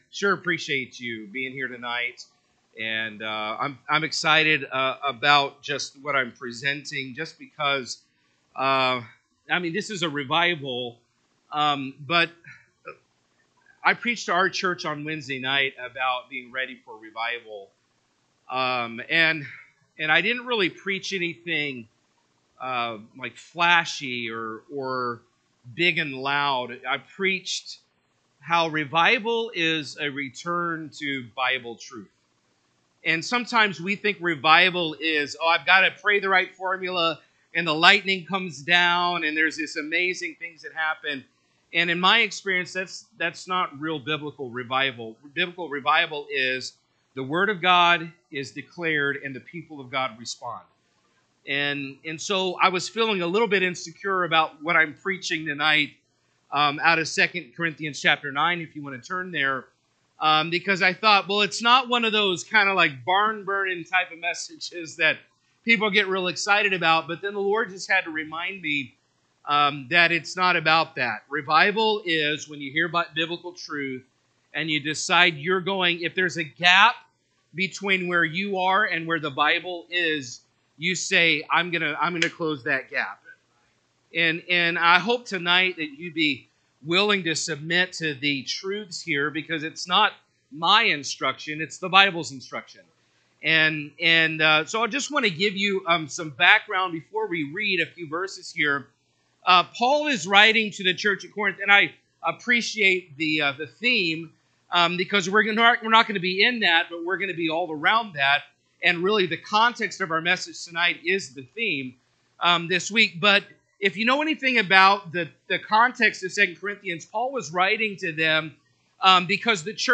September 28, 2025 PM Service 2 Corinthians 9:6-8 (KJB) 6 But this I say, He which soweth sparingly shall reap also sparingly; and he which soweth bountifully shall reap also bountifully. 7&nb…